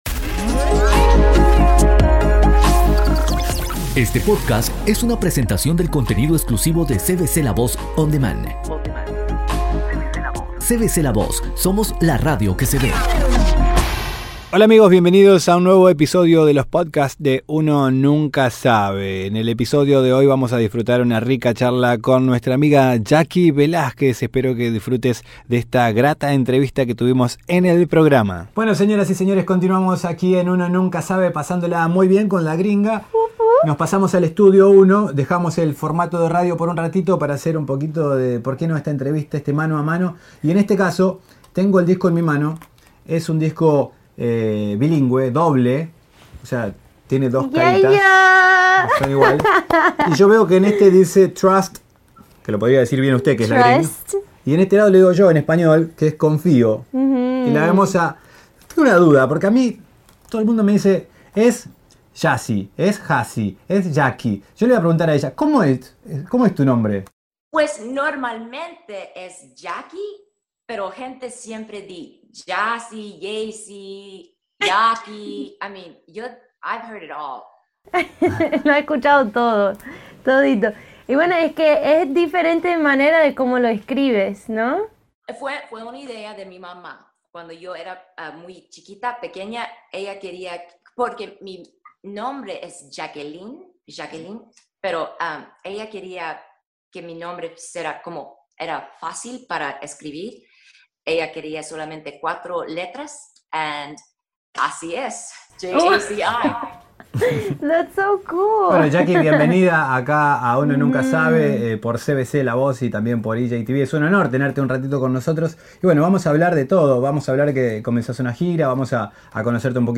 Entrevista con Jaci Velasquez